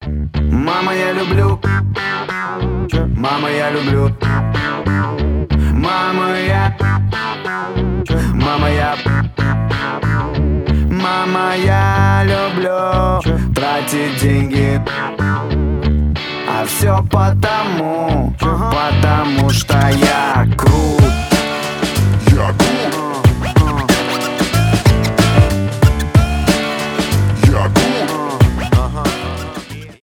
рэп-рок